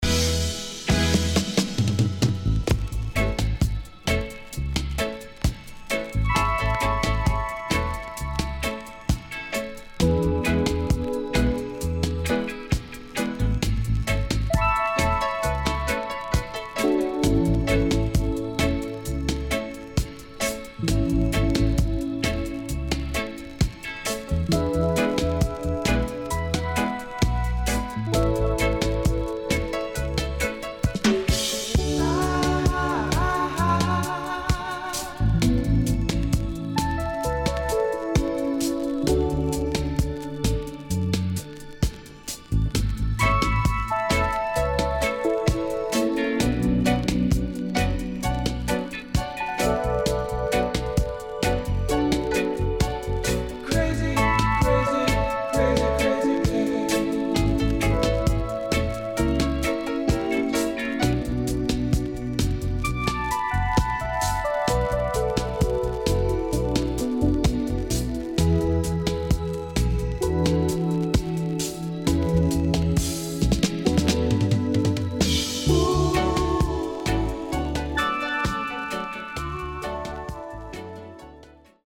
Sweet Female Lovers & Dubwise
SIDE A:所々チリノイズ入ります。